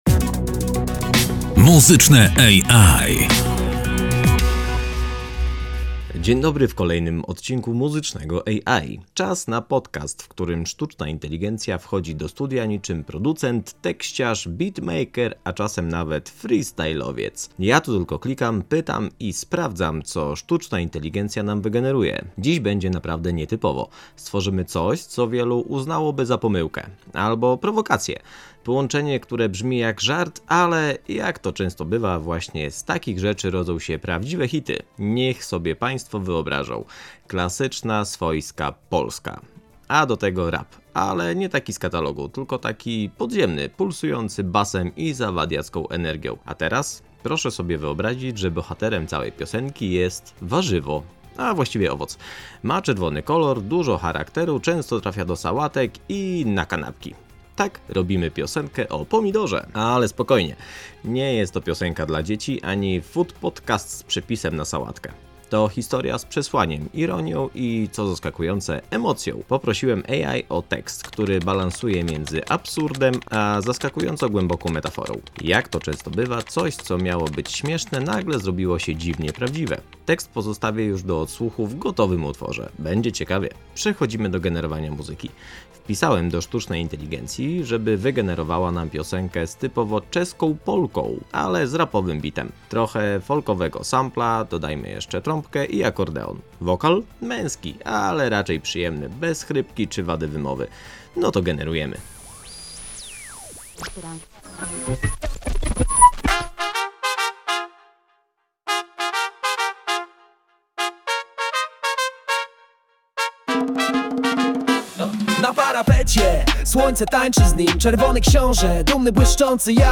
Muzycznie też nie było łatwo: chcieliśmy rapowy bit, ale oparty na klasycznej polce – z folkowym samplem, trąbką, akordeonem i solidną porcją podziemnego beatu. Z kilku wersji wybraliśmy tę, która łączy zadziorność z humorem i pozostawia przestrzeń na… ciszę.